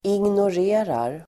Uttal: [ingnår'e:rar]
ignorerar.mp3